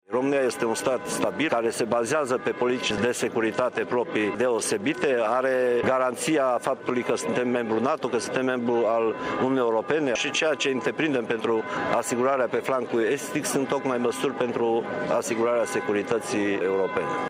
Ministrul apărării, Mircea Duşa, a afirmat, astăzi, la Tîrgu Mureş, că România, NATO şi Uniunea Europeană au luat toate măsurile pentru a asigura securitatea pe flancul estic al Alianţei Nord Atlantice.